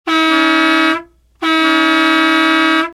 transport
Bus Horns